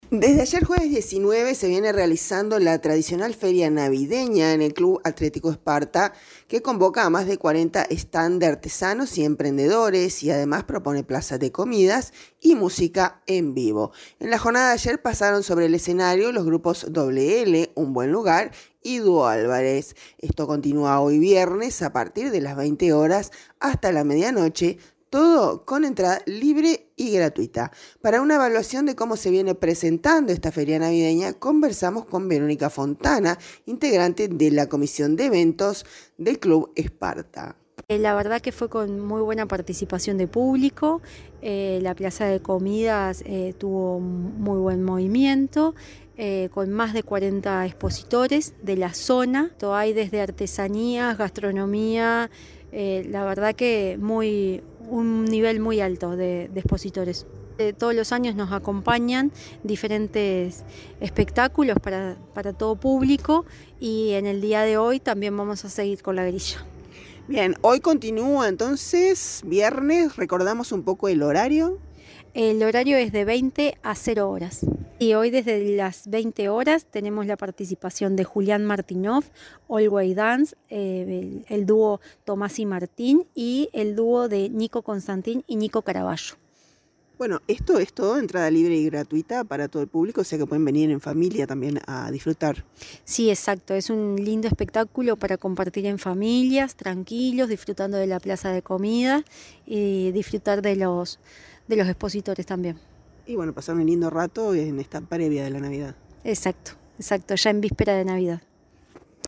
Para una evaluación de como se viene presentando esta Feria Navideña, conversamos con